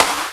Snare Drum 66-01.wav